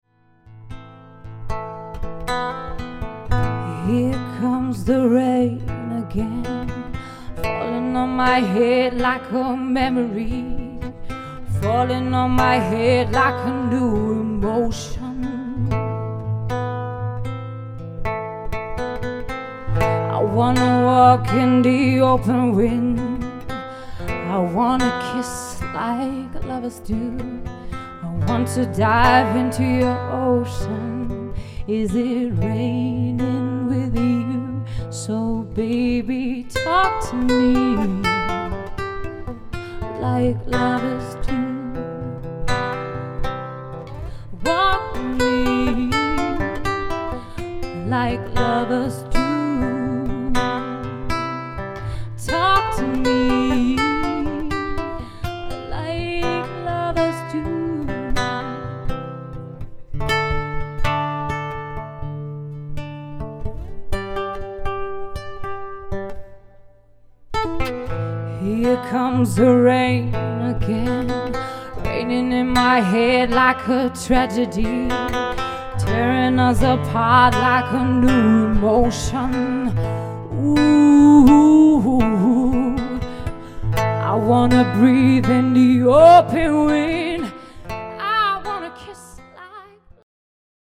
Live in Taunusstein 2018
Akustische Gitarre
Gesang